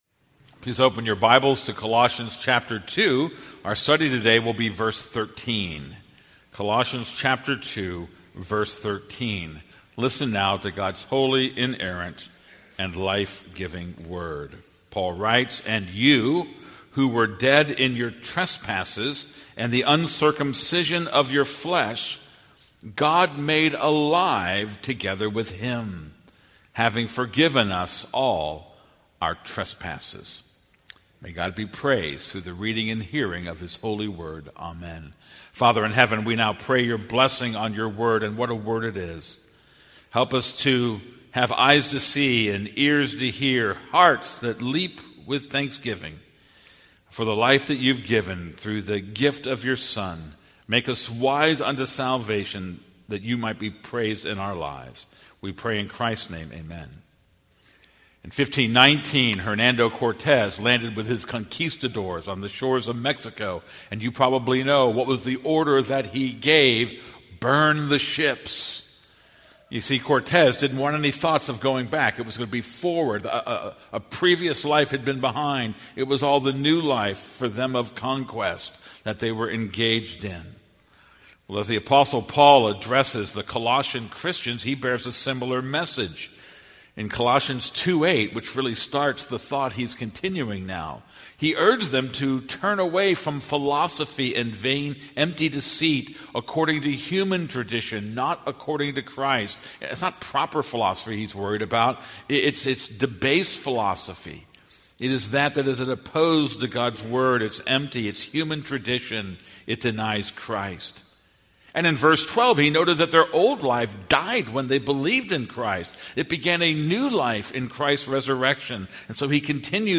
This is a sermon on Colossians 2:13.